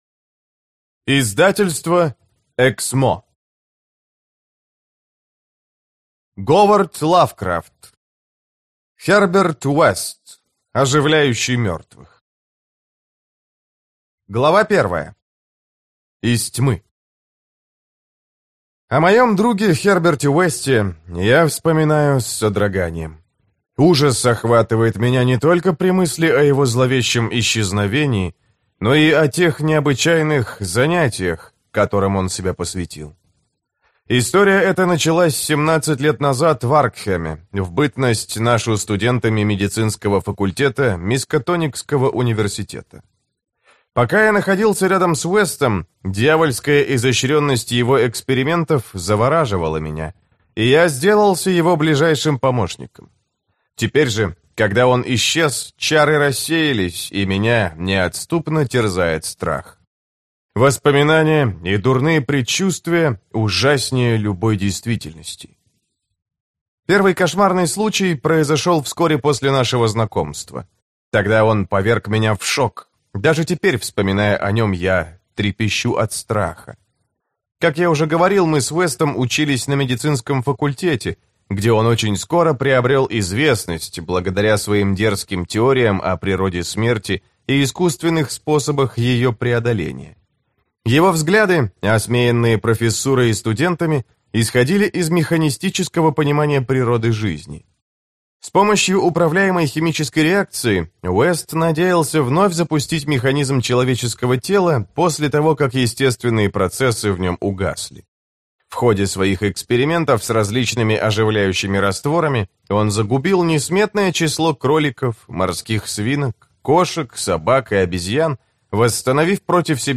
Аудиокнига Херберт Уэст, оживляющий мертвых | Библиотека аудиокниг